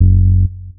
Bass 12.wav